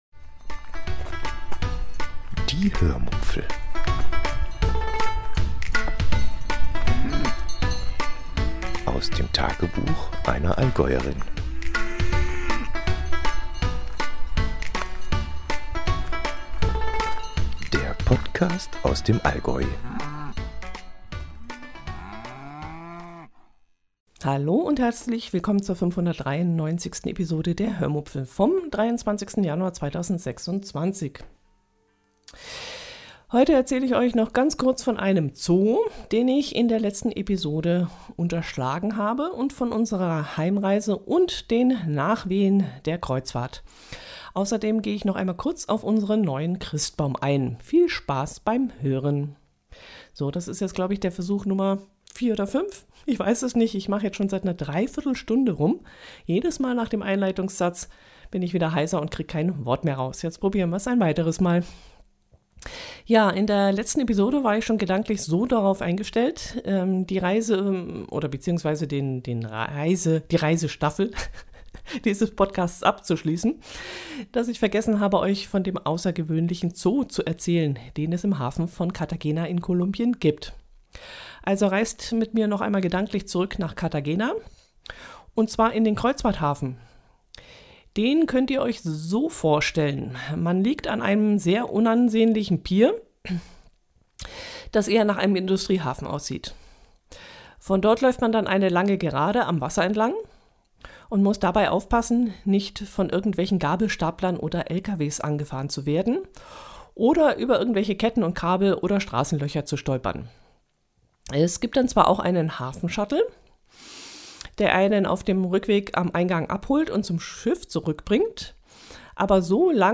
Leider hat mich nach der Reise eine hartnäckige Erkältung erwischt, die bis heute meine Stimme beeinträchtigt – auch darüber spreche ich offen in dieser Folge.